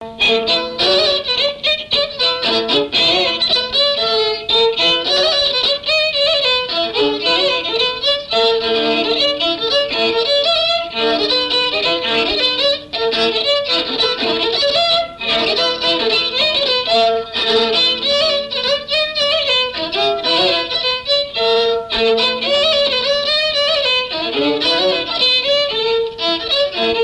Chants brefs - A danser
Pièce musicale inédite